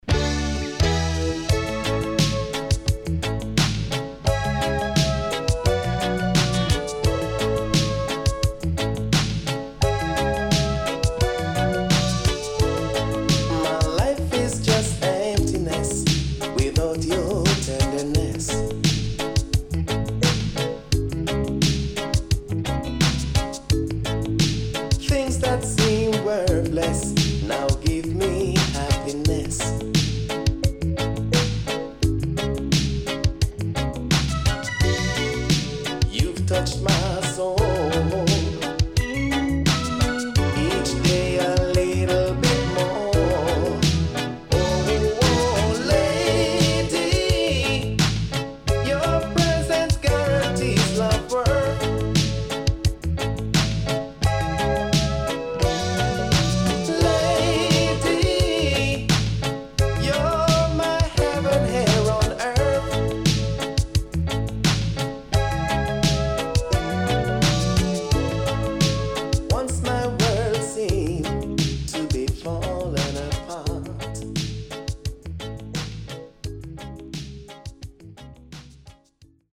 SIDE B:盤質は良好です。